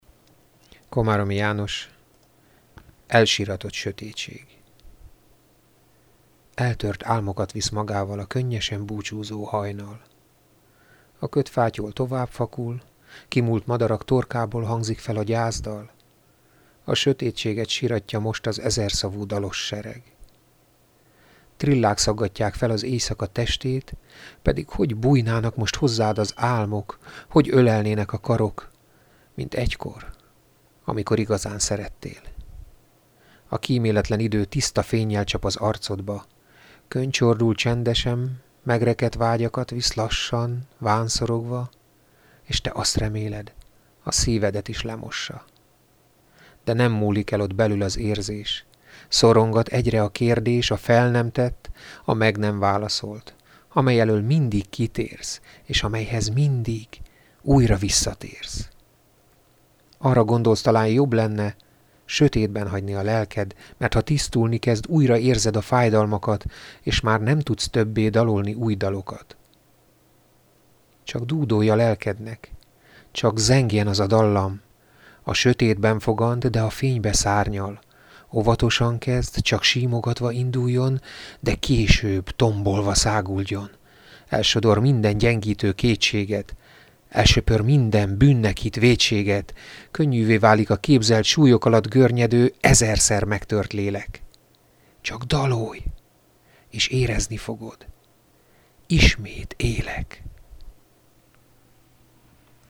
Egyszer azonban gondoltam egyet és elmondtam néhány versemet.